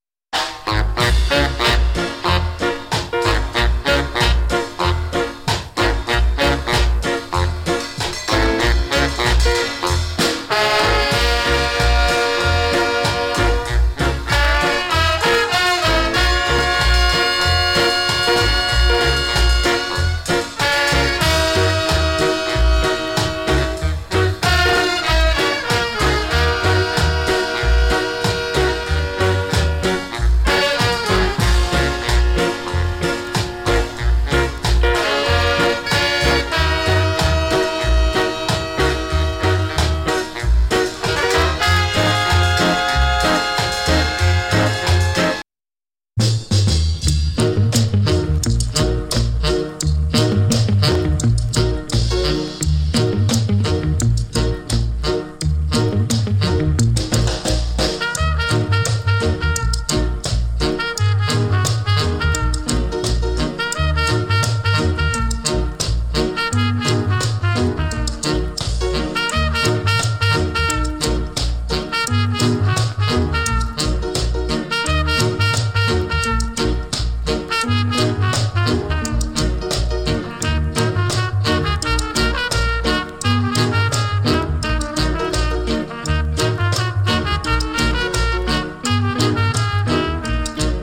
A：VG+ / B：VG+ ＊入荷時よりスリキズ少々有り。ノイズはわずかに有り。